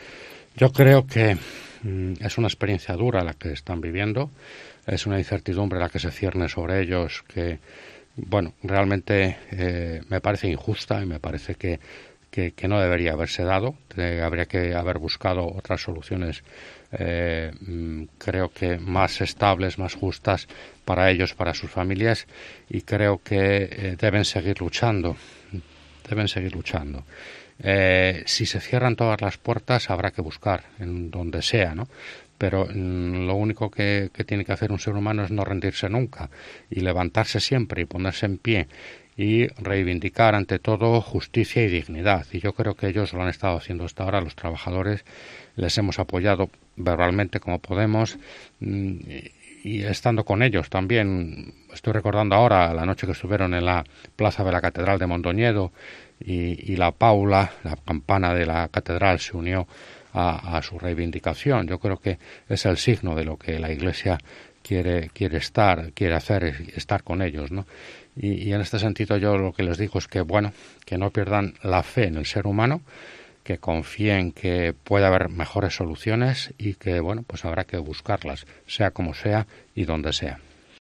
Declaraciones de LUIS ÁNGEL DE LAS HERAS sobre el conflicto de Alcoa